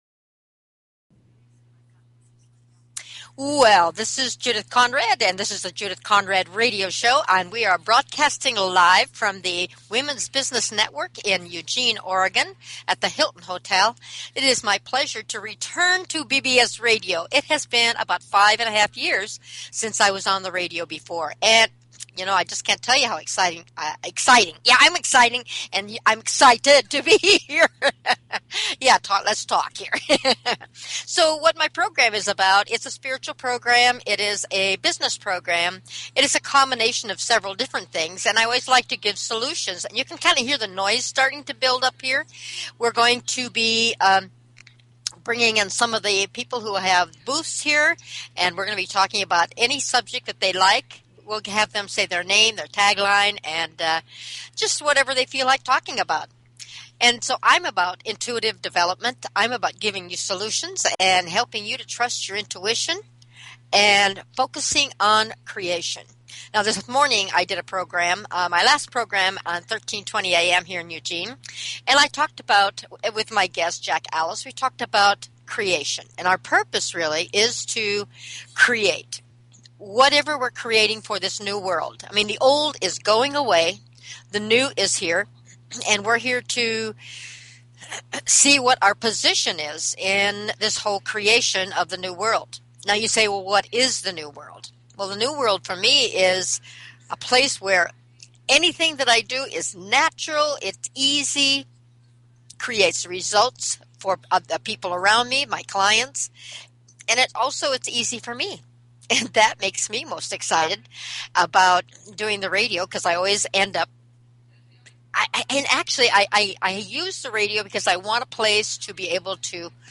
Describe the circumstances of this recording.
This program was broadcast at the Hilton at the Women's Business Network. It was a bit chaotic.